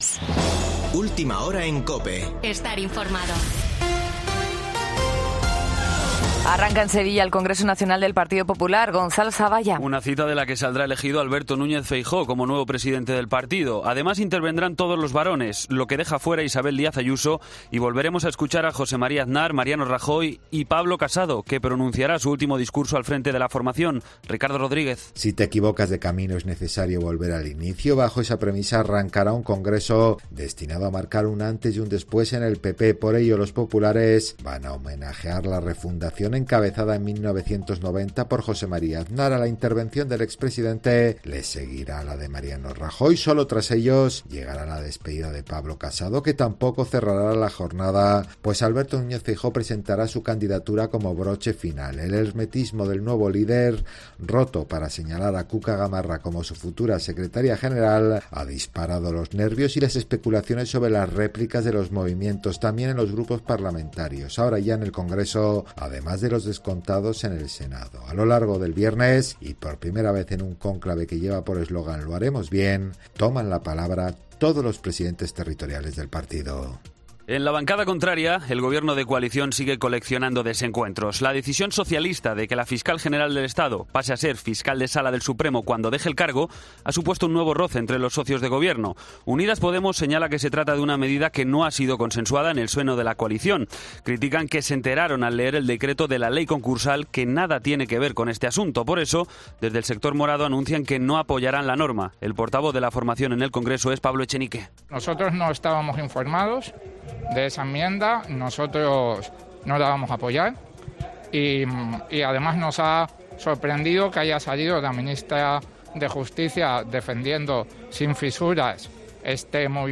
Boletín de noticias de COPE del 1 de abril de 2022 a las 3.00 horas